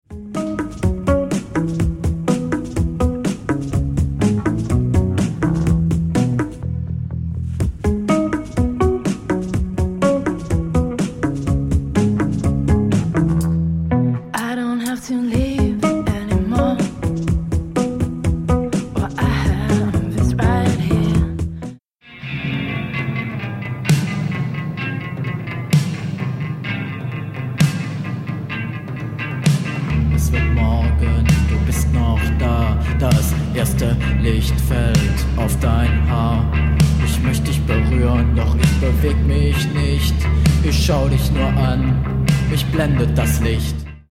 German post-punk band